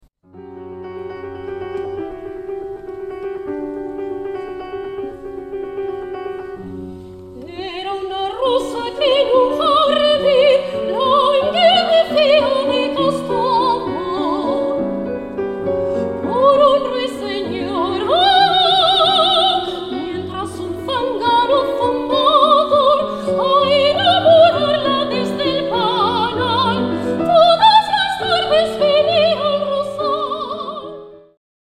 GRAN NOCHE DE ZARZUELA
Abenduaren 6an (22:00, 10€/12€) 'GRAN NOCHE DE ZARZUELA', Lazkaoko Kantaka Abesbatzaren kontzertu freskoa, koloretsua eta dinamikoa.